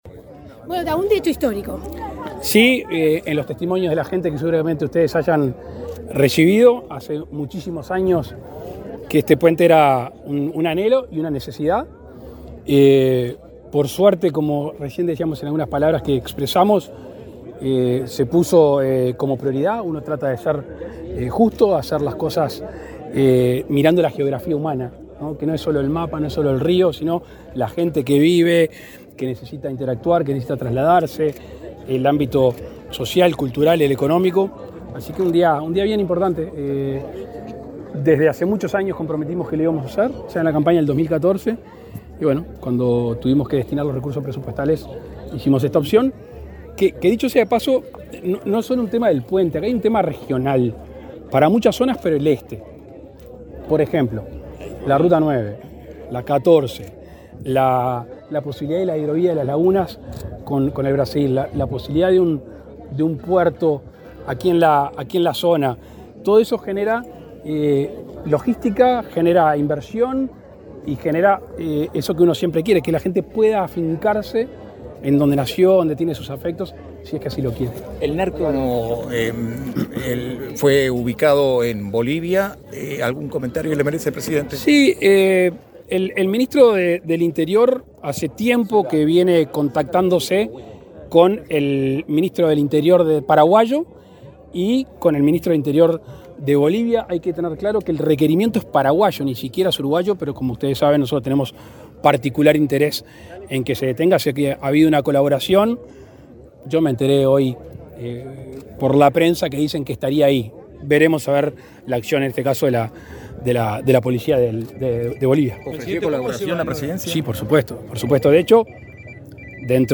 Declaraciones del presidente Lacalle Pou a la prensa
Declaraciones del presidente Lacalle Pou a la prensa 31/07/2023 Compartir Facebook X Copiar enlace WhatsApp LinkedIn Este lunes 31, el presidente de la República, Luis Lacalle Pou, dialogó con la prensa luego de encabezar el acto de inauguración de un puente sobre el río Cebollatí.